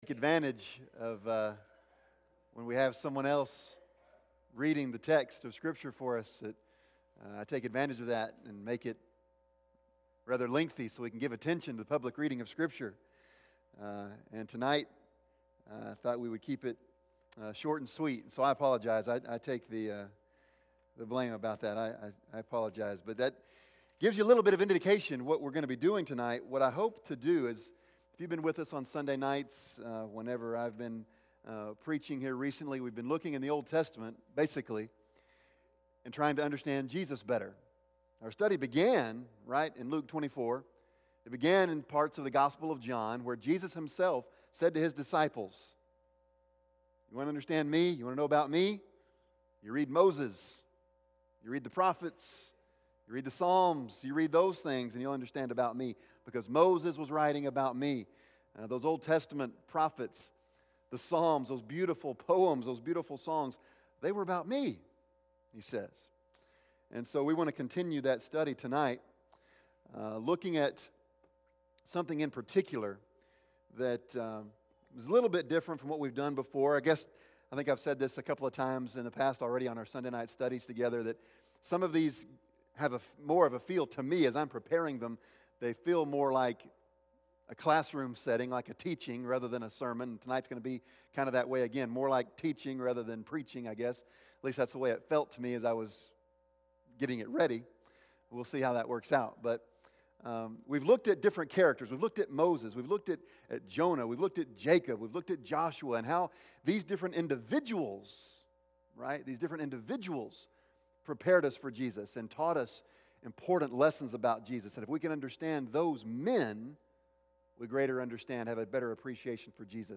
Bible Text: Matthew 4:1-11 | Preacher